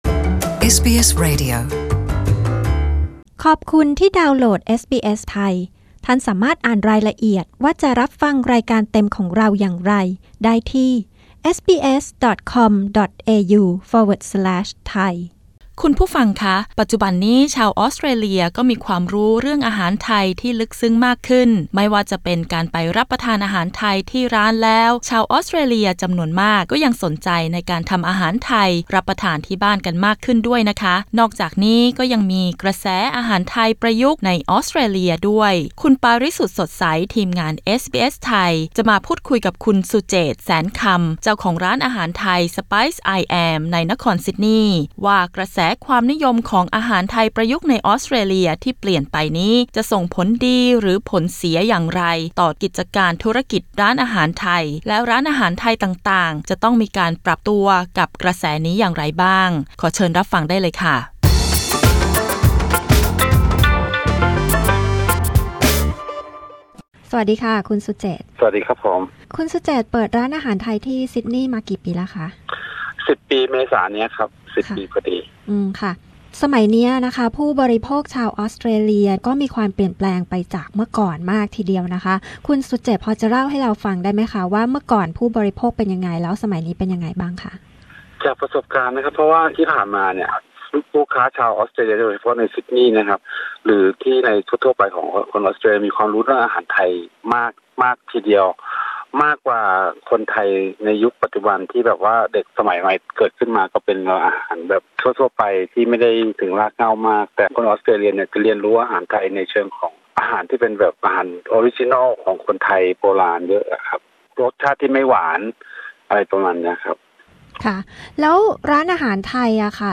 กดปุ่ม 🔊 ด้านบนเพื่อฟังรายงานเรื่องนี้เป็นภาษาไทย